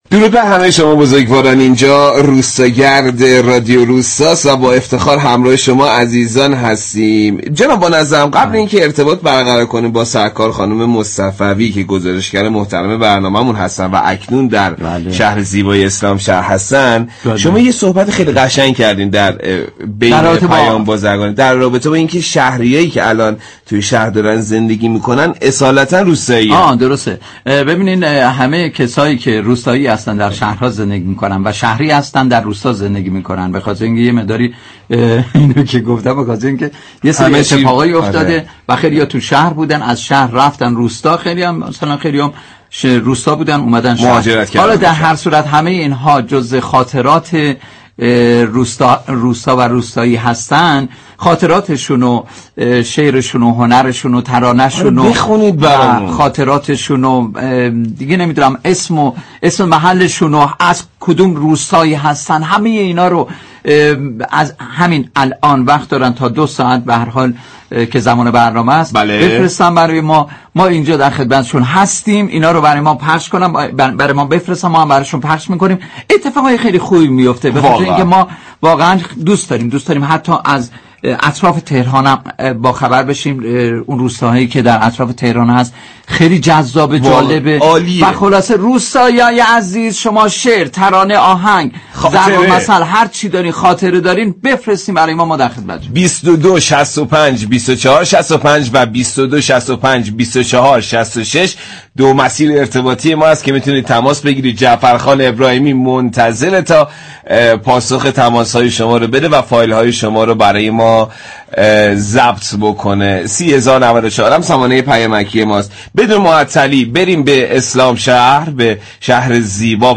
بخشدار مركزی شهرستان اسلامشهر در گفتگو با روستاگرد رادیو تهران عنوان كرد: ایجاد زیرساخت‌های مهم در اسلام شهر و اقدامات مهمی كه در روستاهای اسلامشهر انجام شده موجب مدیریت بحران‌های این خطه از استان تهران خواهد شد.